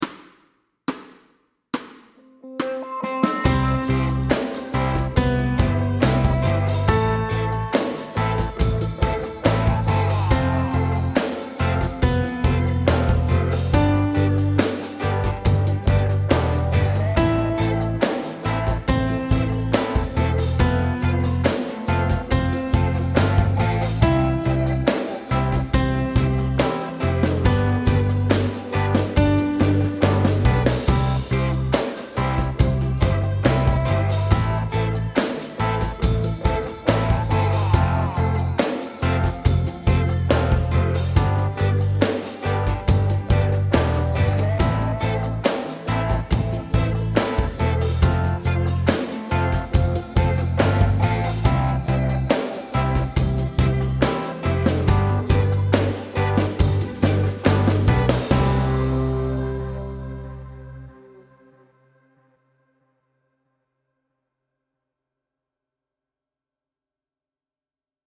Medium C instr (demo)
Note values are whole notes, half notes and rests.